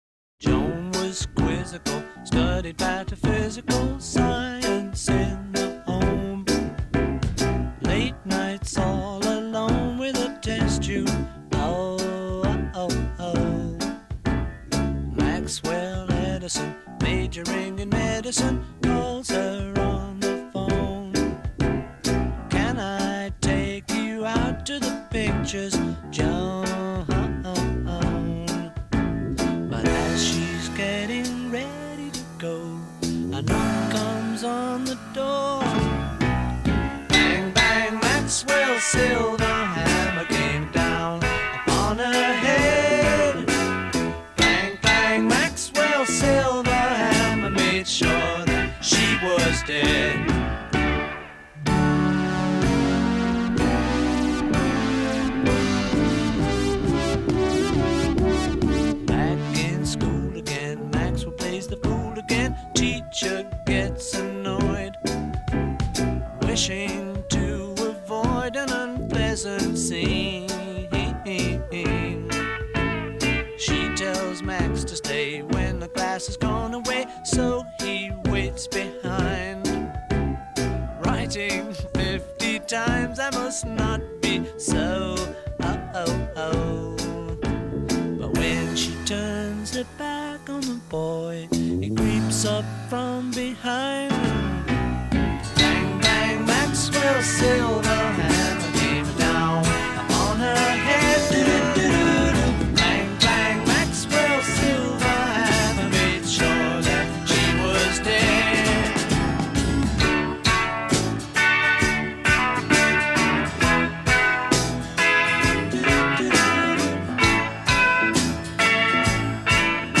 MP3 file created from customer's M4A file (using iTunes 6)
What I heard was there was about a 3 second tail of silence at the end of the MP3.